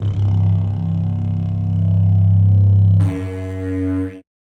Minecraft Version Minecraft Version latest Latest Release | Latest Snapshot latest / assets / minecraft / sounds / item / goat_horn / call3.ogg Compare With Compare With Latest Release | Latest Snapshot